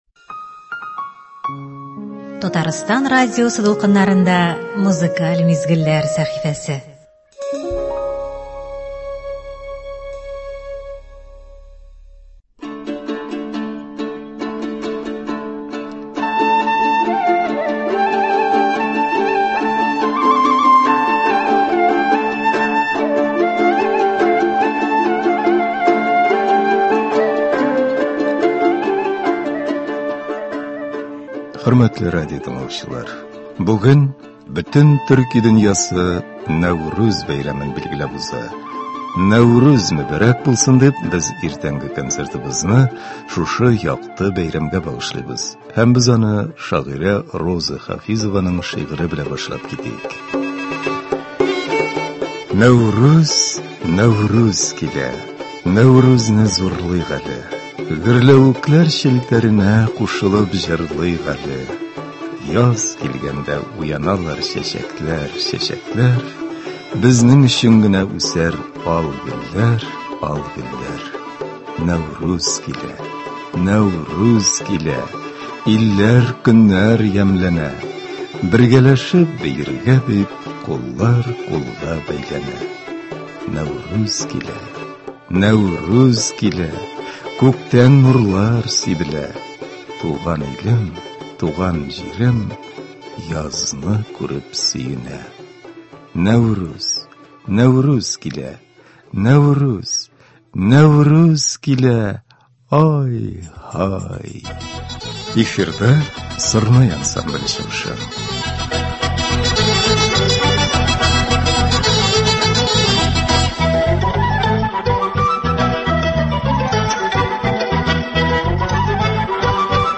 Музыкаль программа.